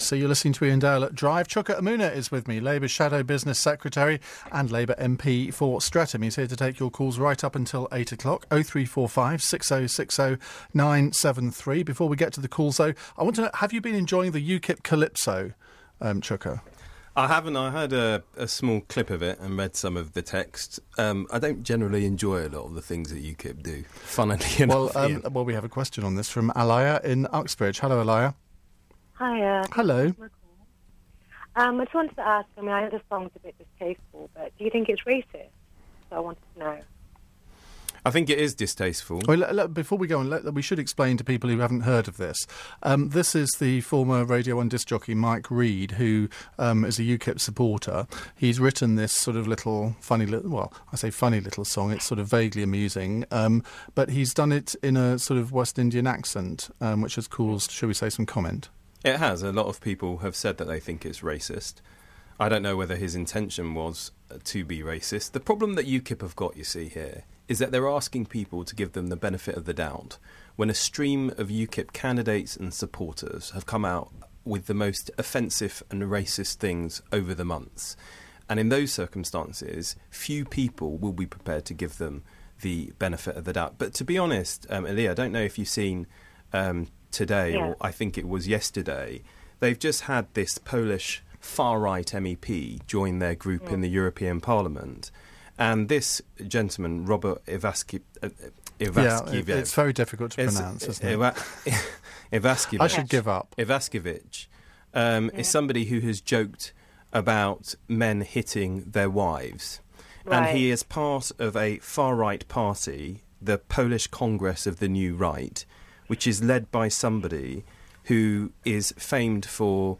Speaking to LBC Radio, Shadow Business Secretary Chuka Umunna says the Ukip Calypso song, which features former Radio 1 DJ Mike Read singing in a Caribbean accent, is "distasteful".